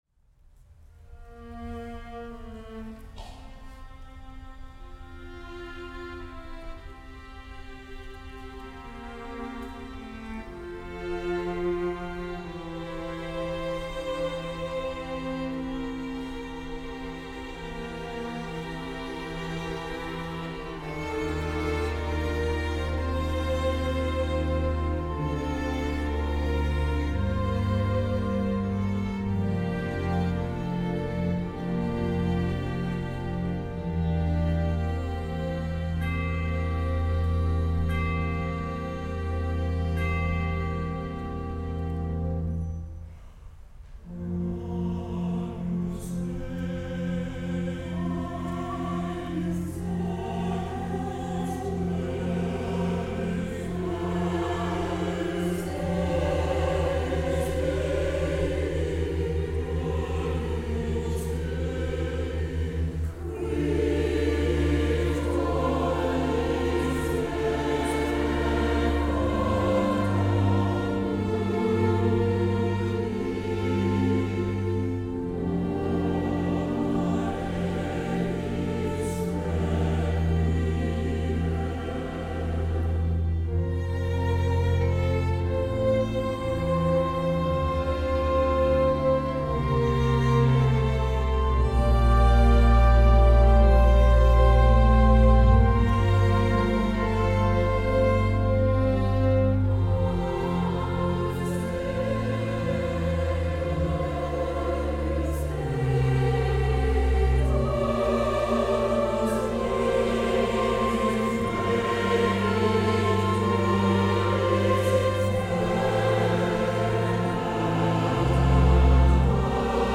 NATIONAL SEMI-FINALIST: 2023 THE AMERICAN PRIZE IN COMPOSITION – CHORALE MUSIC: Agnus Dei (2022) for chamber orchestra, organ, and SATB from REQUIEM (2022).
for SATB, chamber orchestra, and organ